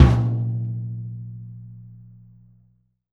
TOM63--01 -R.wav